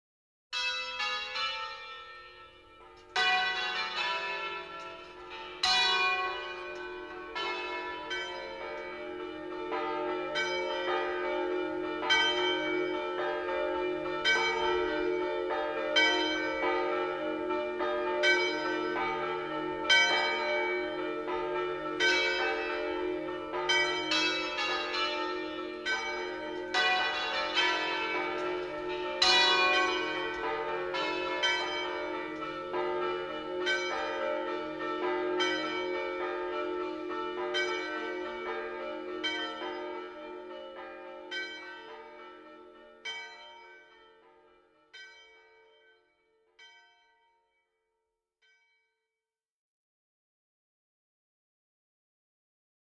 Bells – Campanile (steeple) from canton Ticino